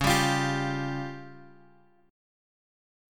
C#Mb5 chord {x 4 5 x 6 3} chord
Csharp-Major Flat 5th-Csharp-x,4,5,x,6,3.m4a